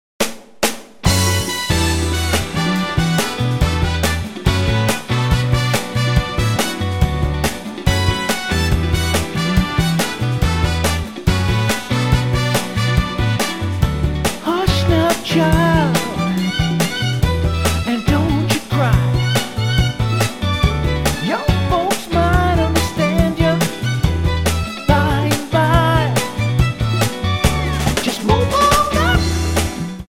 Tonart:Bm Multifile (kein Sofortdownload.
Die besten Playbacks Instrumentals und Karaoke Versionen .